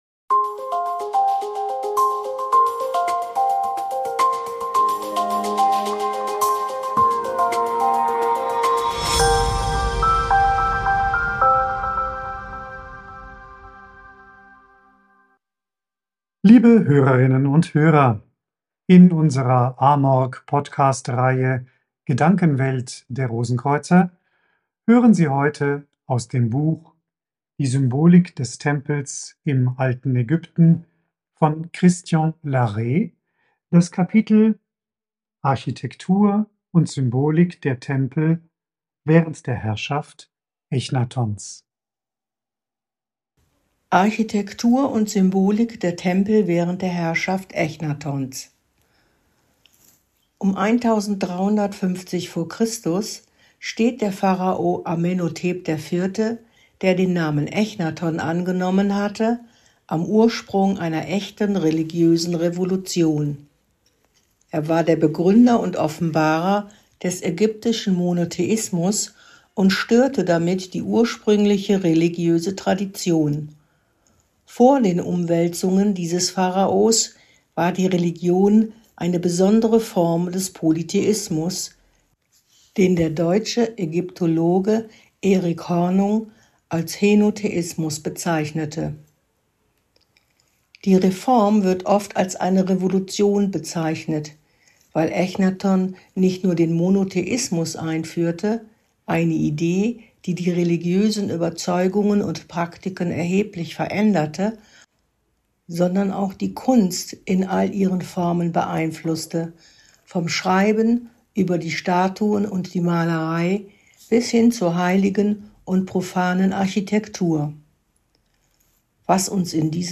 liest einen Auszug aus dem Buch